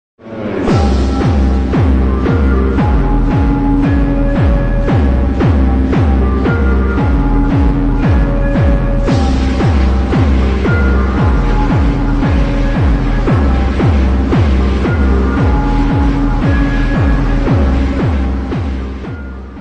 Jumpstyle Bootleg